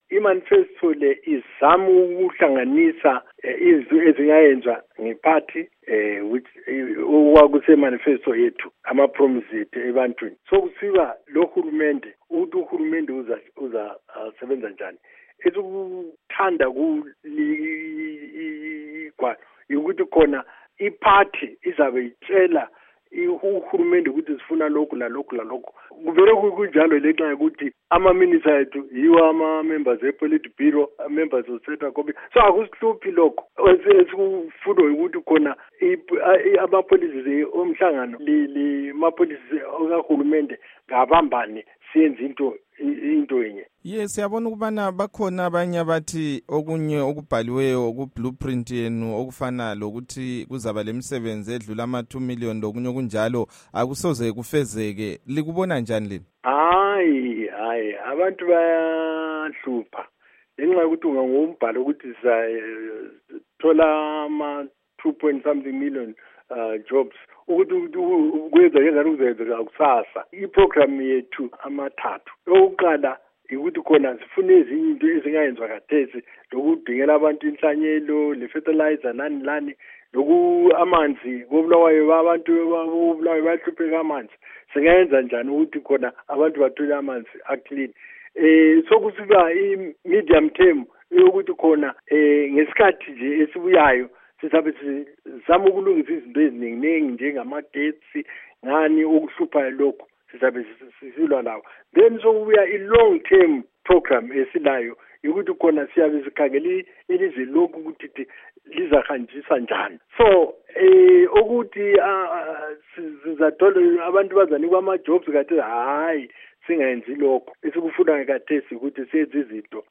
Ingxoxo loMnu.Rugare Gumbo